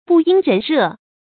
不因人热 bù yīn rén rè
不因人热发音
成语注音 ㄅㄨˋ ㄧㄣ ㄖㄣˊ ㄖㄜˋ